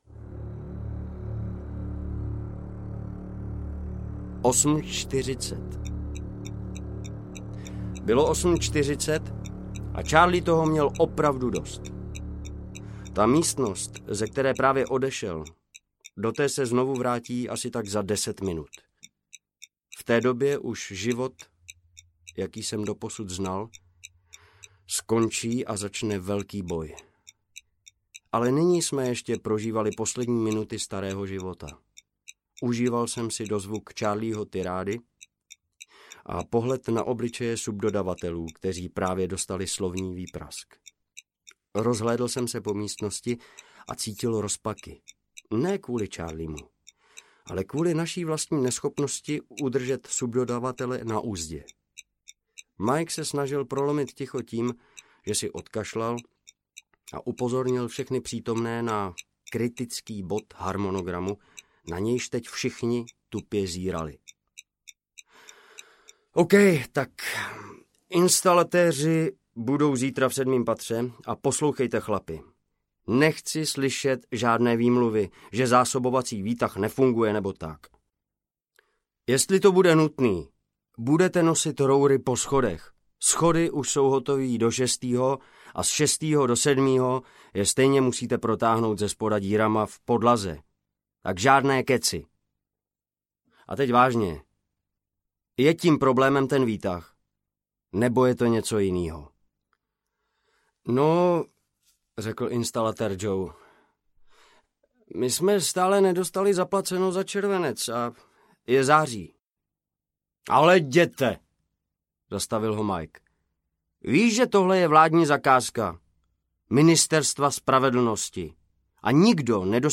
Audiokniha Věže, příběh 11.září, kterou napsal Jiří Boudník. Strhující a pravdivý příběh česko-amerického architekta, přímého účastníka pádu newyorských věží.
Ukázka z knihy